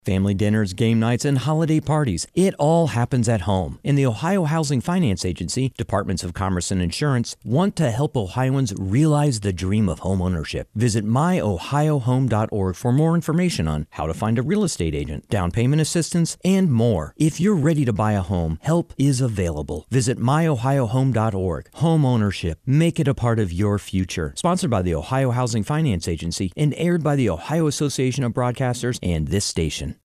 :30 Radio Spot